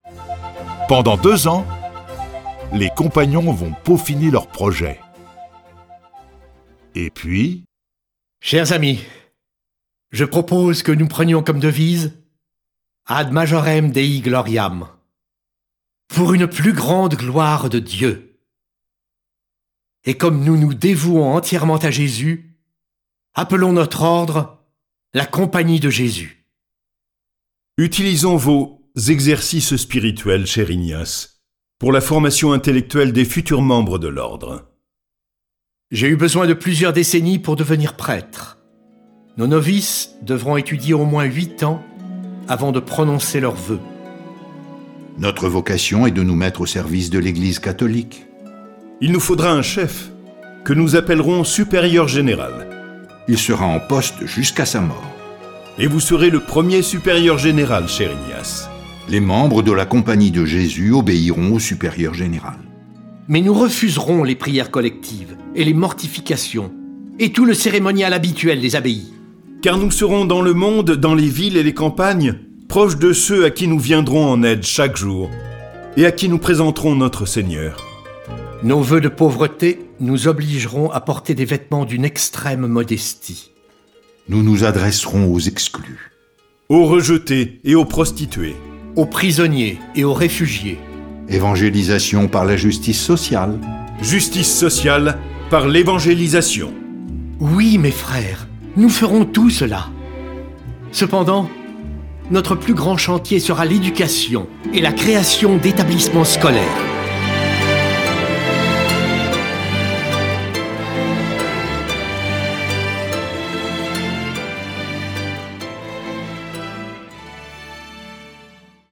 Diffusion distribution ebook et livre audio - Catalogue livres numériques
Cette version sonore de la vie de saint Ignace de Loyola est animée par 8 voix et accompagnée de plus de 30 morceaux de musique classique.